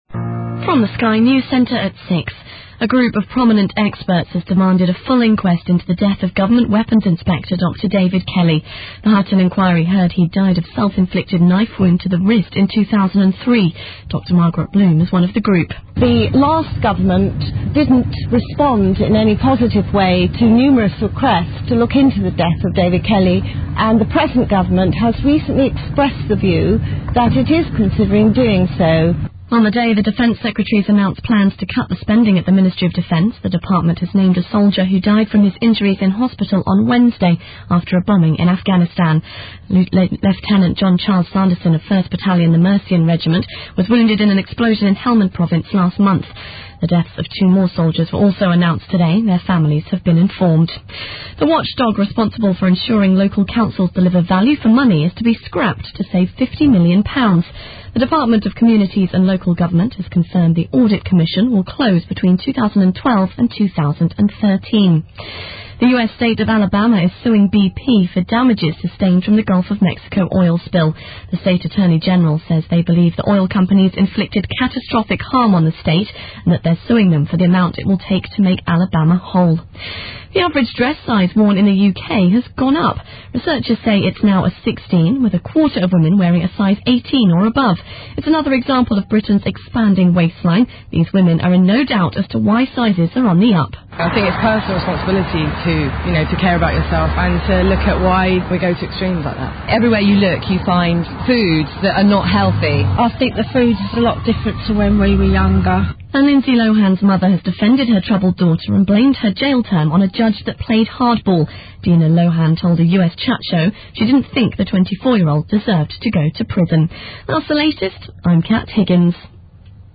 Drivetime - 5pm - 7pm Friday. Friday Drivetime - Bristol’s weekly current affairs round-up.